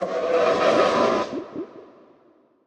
Minecraft Version Minecraft Version snapshot Latest Release | Latest Snapshot snapshot / assets / minecraft / sounds / mob / horse / skeleton / water / idle1.ogg Compare With Compare With Latest Release | Latest Snapshot